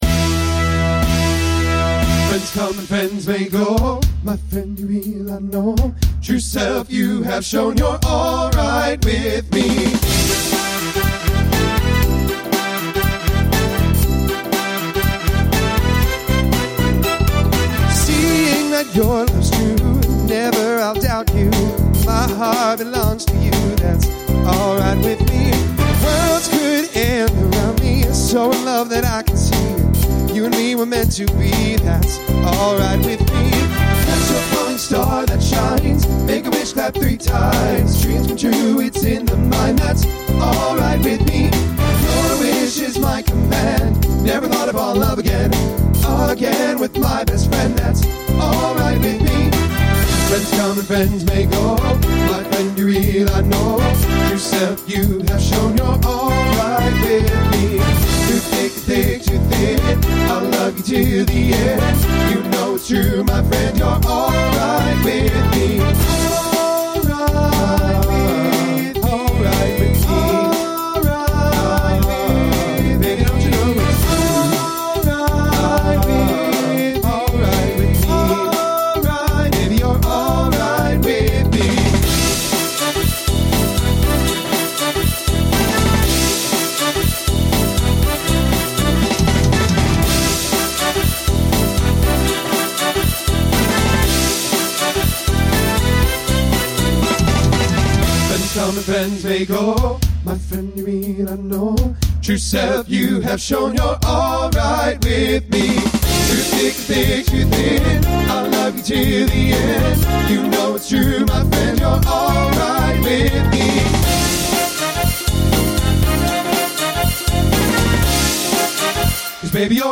TTB Instrumental combo
Pop/Dance